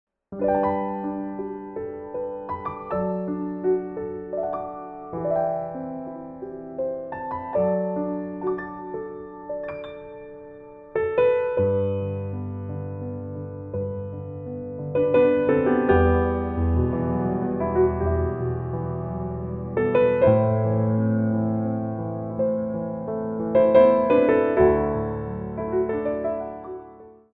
Piano Arrangements of Pop & Rock for Ballet Class
3/4 (8x8)